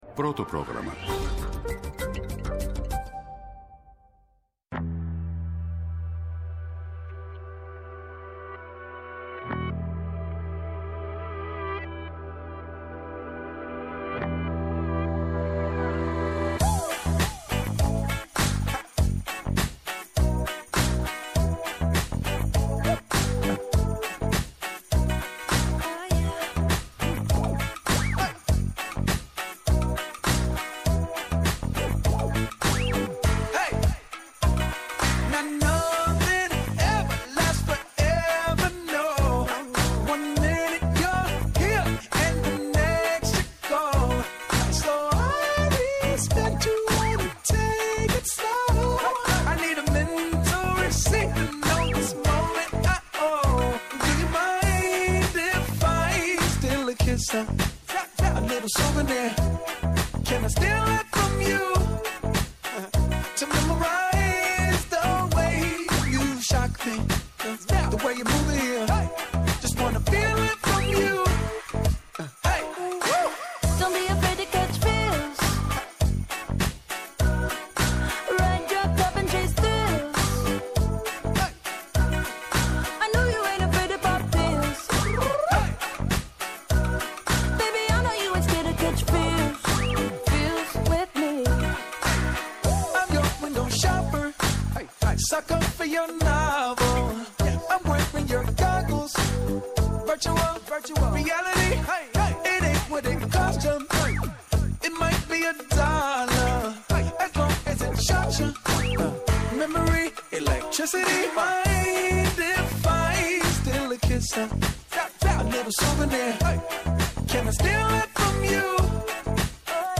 Κάθε Σάββατο στις 12, ο ψηφιακός κόσμος μετατρέπεται σε ραδιοφωνική εμπειρία στο Πρώτο Πρόγραμμα.
Με αναλύσεις, πρακτικές συμβουλές και συνεντεύξεις με πρωτοπόρους στην τεχνολογία και τη δημιουργικότητα, το «Κλικ στο Πρώτο» σας προετοιμάζει για το επόμενο update.